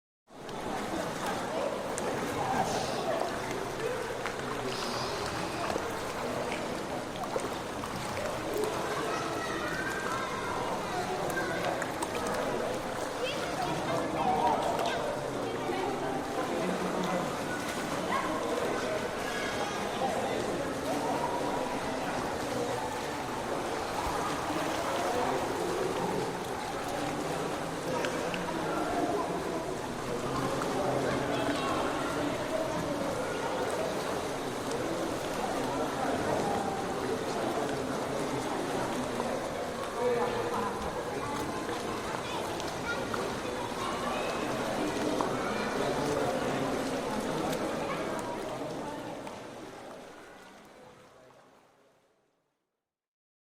Kids at the Pool Ambiance
Ambient
yt_Z59qm9nf-tc_kids_at_the_pool_ambiance.mp3